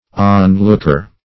On-looker \On"-look`er\, n.